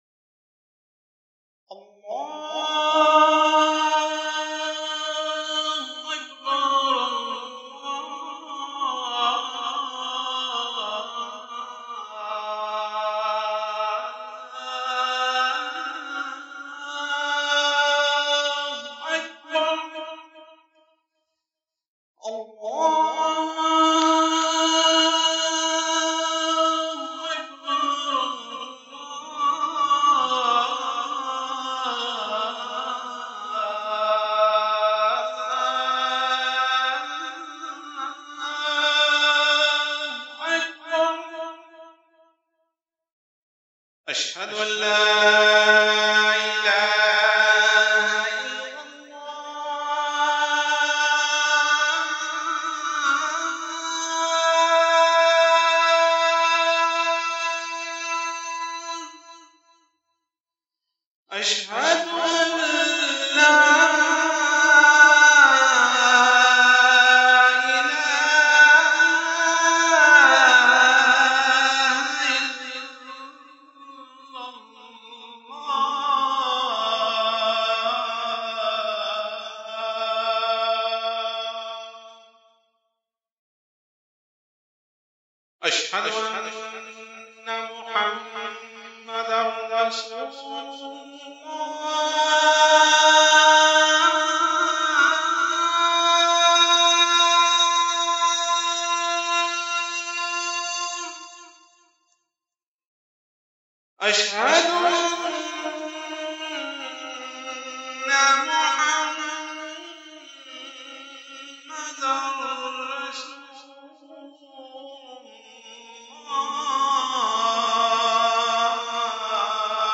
Azan